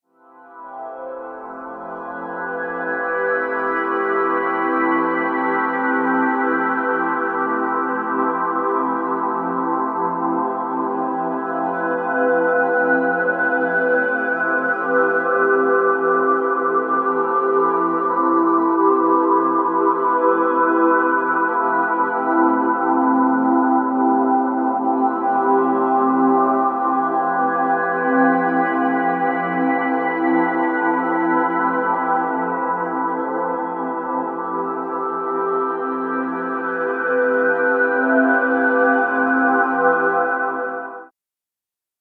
Одна нота и пэд из неё. Без ревера.